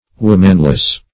Womanless \Wom"an*less\, a. Without a woman or women.